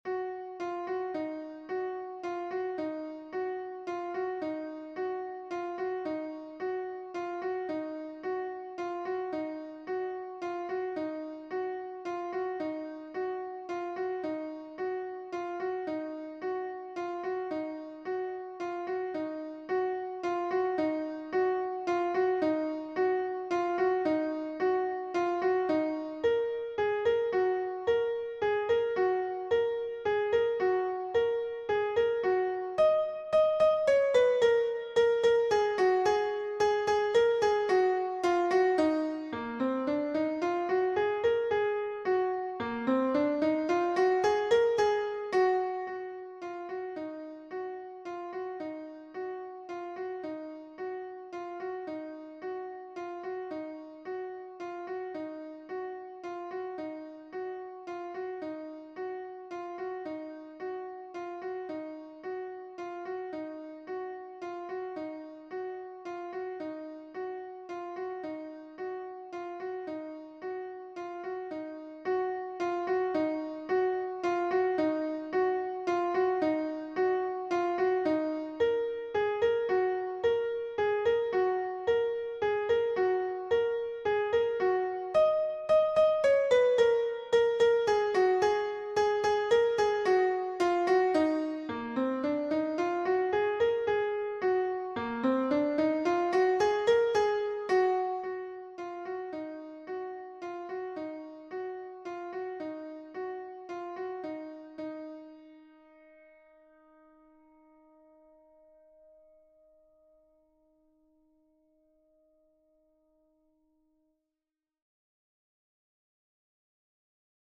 MP3 version piano
Soprano